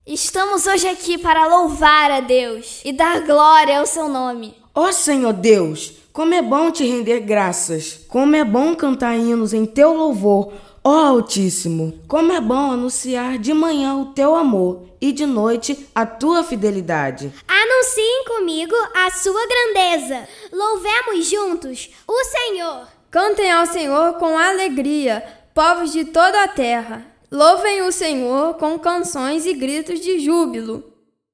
01 - Narração 01